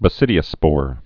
(bə-sĭdē-ə-spôr)